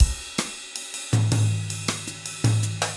Mambo 2
Straight / 160 / 2 mes
MAMBO1 - 160.mp3